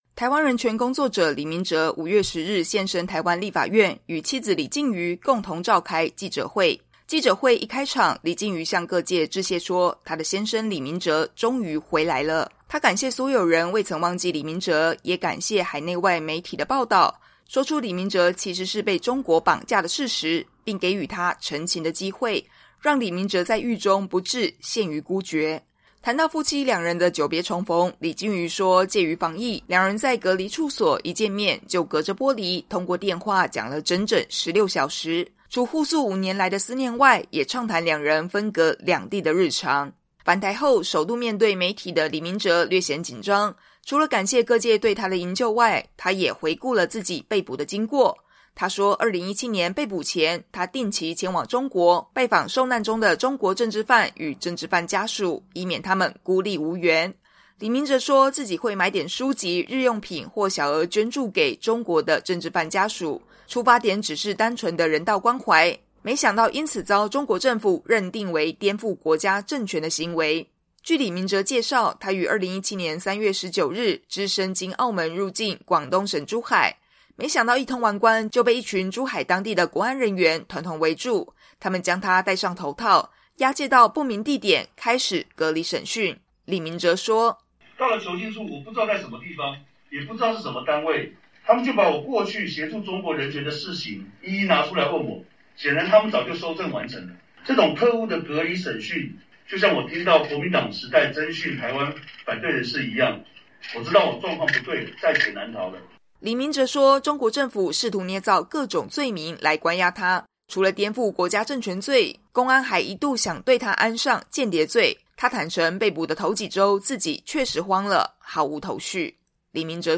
台湾人权工作者李明哲在记者会上讲述被中国大陆审判和监禁的经历。（2022年5月10日）